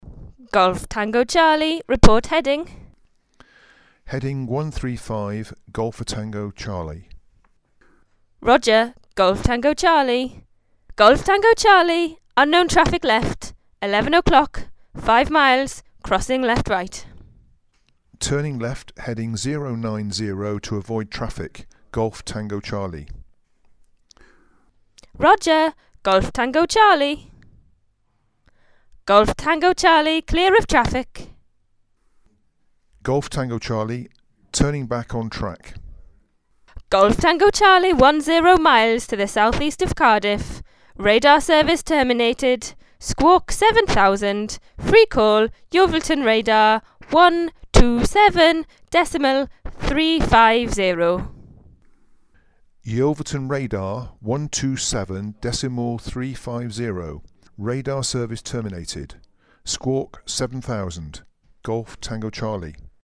Listen to the relevant exchanges between pilot and ground (links are in the text).
Audio 3. This  being a Traffic Service, the  ATC Informs the pilot of traffic in the  vicinity – but it is up to the pilot to decide what avoiding action to take